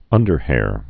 (ŭndər-hâr)